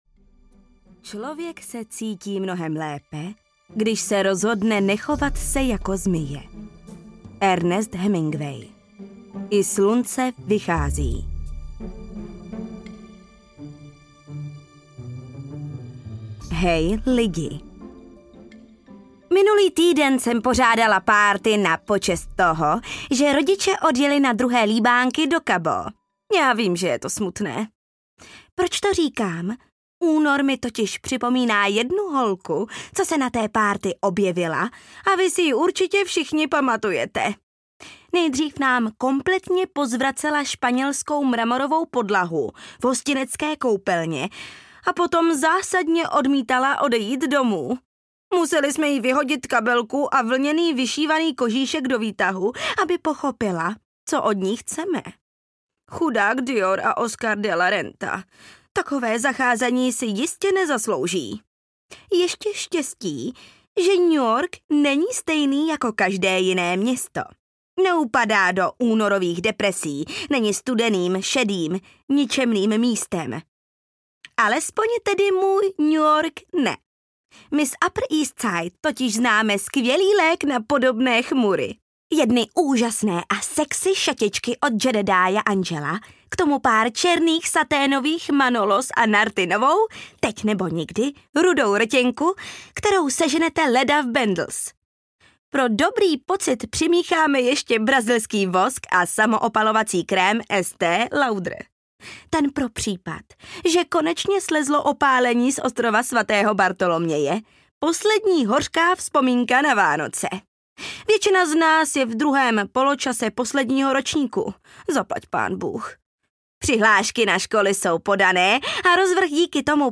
Gossip Girl: Protože znám svou cenu audiokniha
Ukázka z knihy